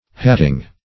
Search Result for " hatting" : The Collaborative International Dictionary of English v.0.48: Hatting \Hat"ting\ (h[a^]t"t[i^]ng), n. The business of making hats; also, stuff for hats.